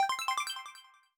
Sound effect of 1-Up (World Map) in Super Mario Bros. Wonder